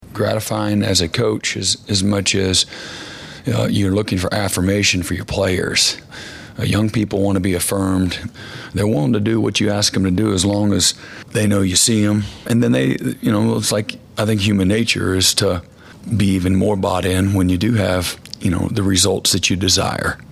Oklahoma head football coach Brent Venables took the podium on Tuesday afternoon to talk about the Sooner’s hot start and OU’s Big 12 opener.